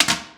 household
Toilet Seat Drop Flappy Lid 2